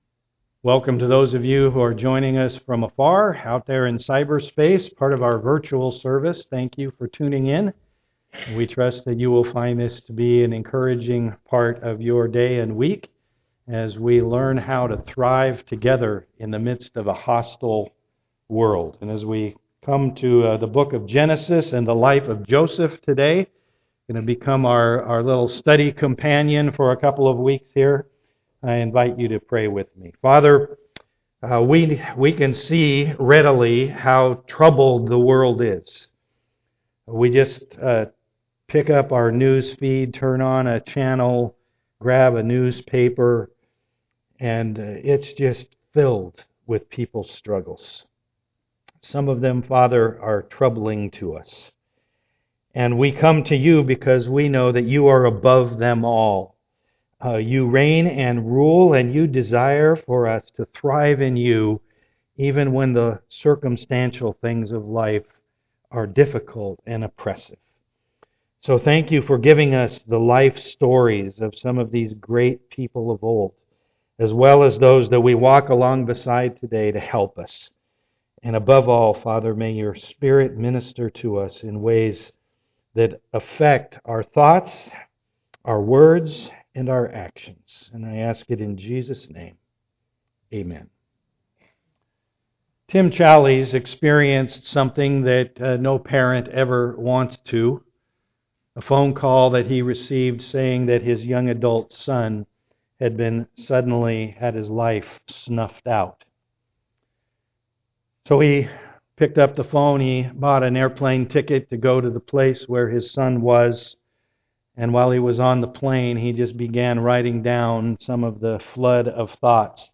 39:1-4 Service Type: am worship The world is rough.